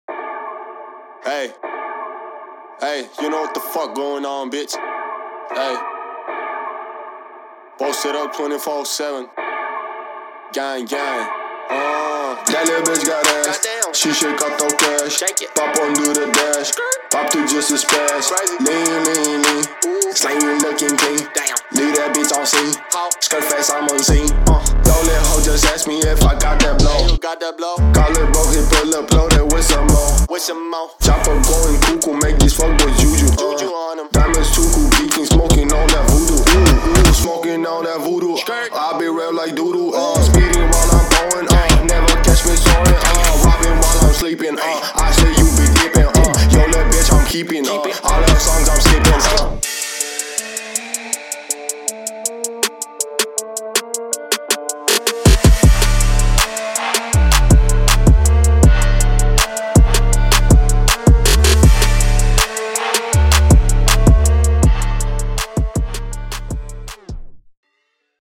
Trap